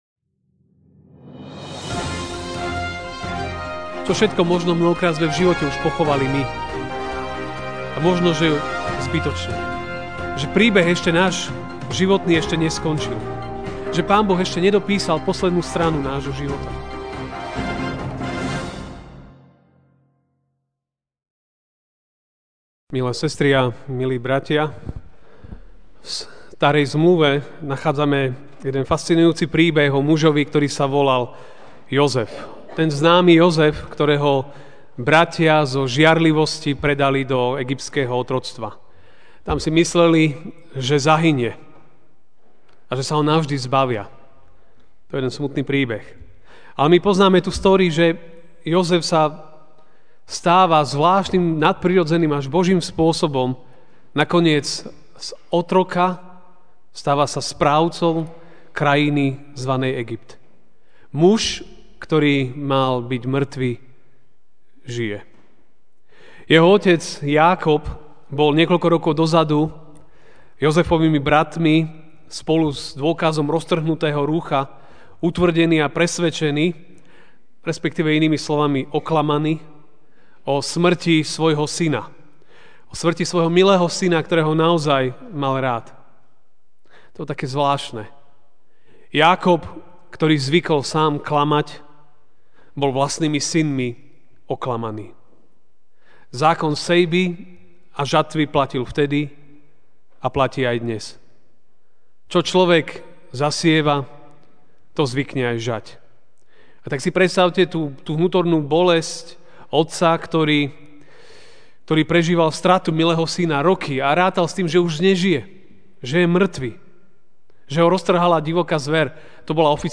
MP3 SUBSCRIBE on iTunes(Podcast) Notes Sermons in this Series Ranná kázeň: Syn žije! (1.M. 45, 26 ) A oznámili mu: Jozef ešte žije, ba panuje nad celým Egyptom.